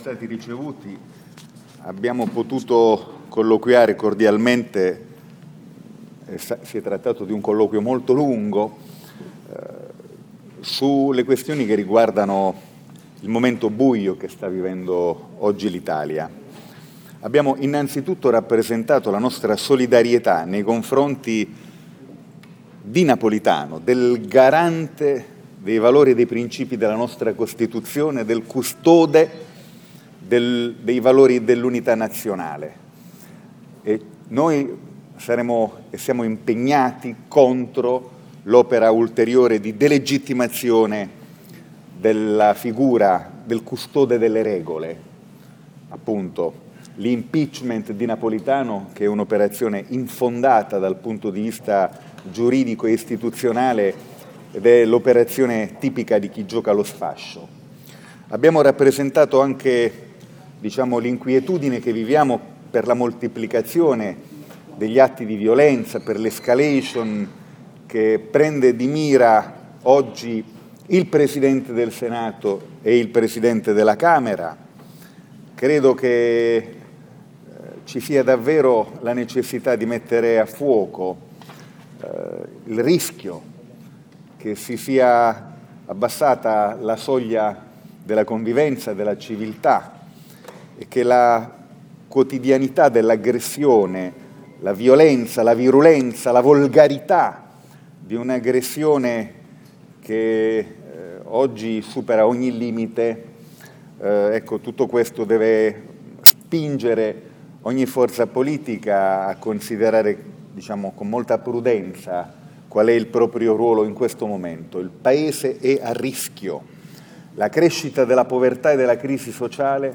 file audio delle dichiarazioni del presidente di Sel dopo l’incontro.